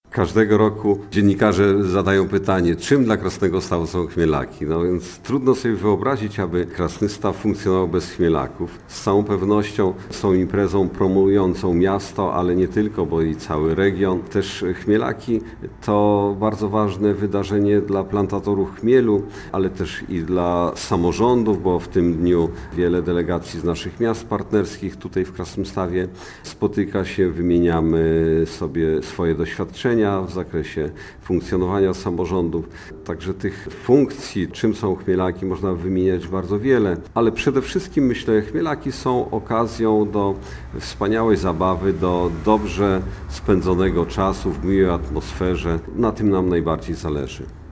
Ogólnopolskie Święto Chmielarzy i Piwowarów "Chmielaki Krasnostawskie" to jedyna taka impreza w Polsce i okazja, aby przeżyć niezapomniany, wakacyjny weekend. Dla miasta to również doskonała promocja - mówi burmistrz Jakubiec